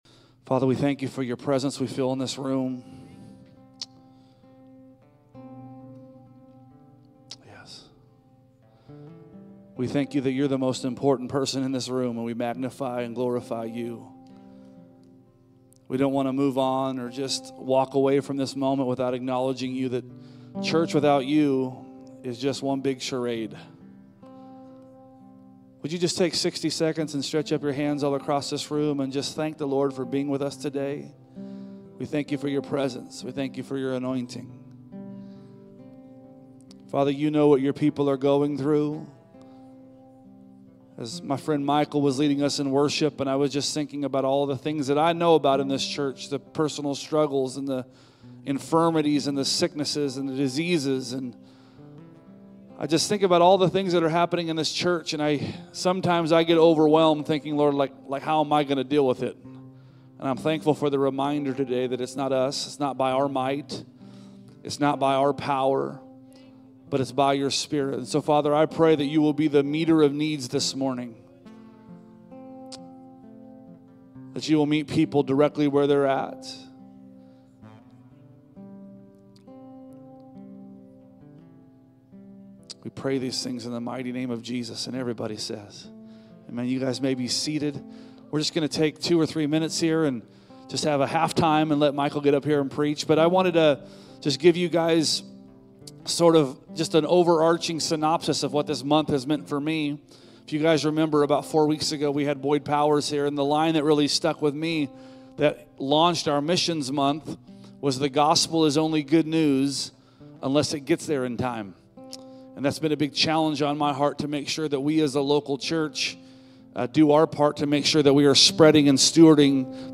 A message from the series "Sunday Specials."